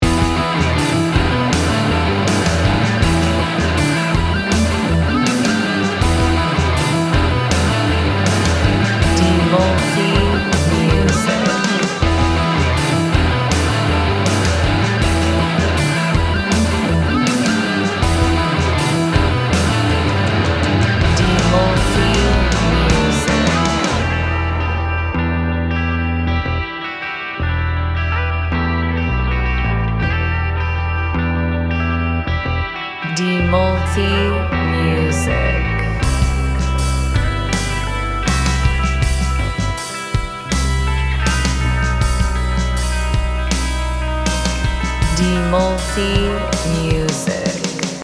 Gym Music Instrumental
Cepat, keras, tanpa kompromi.